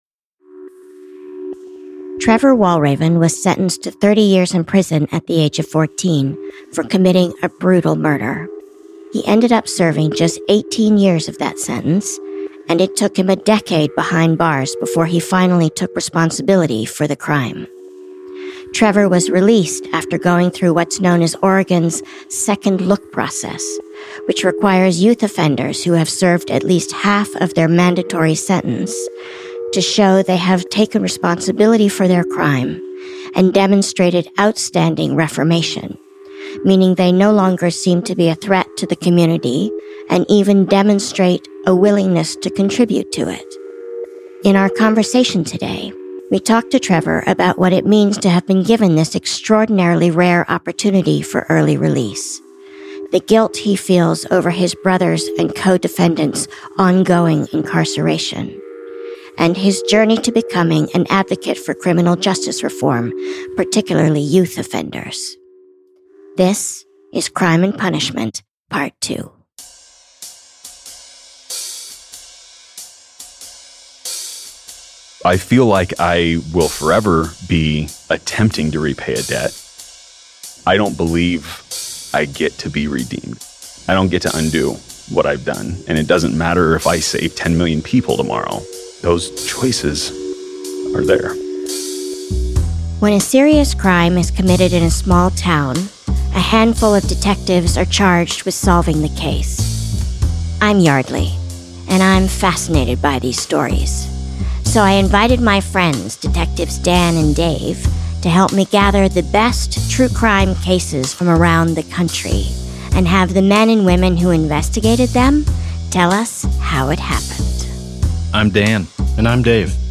In this two-part conversation